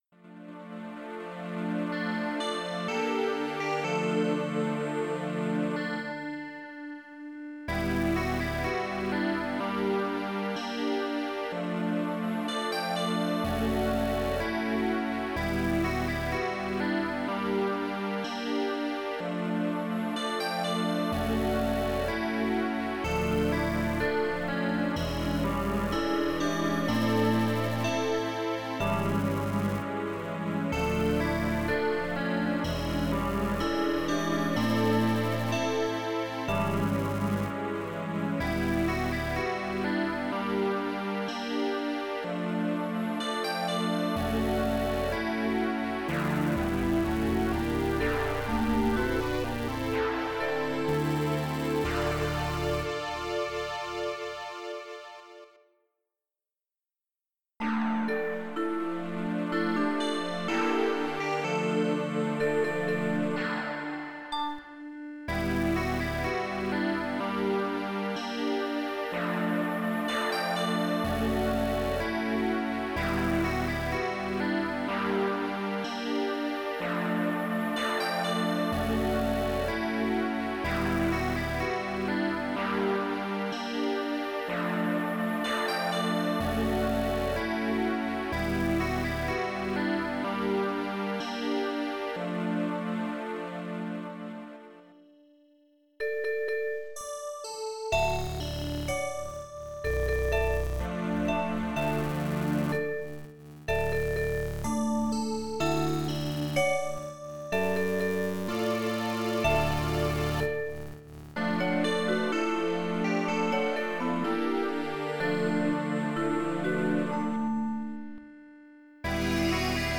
(A long slept hazy dream in an electronic landscape)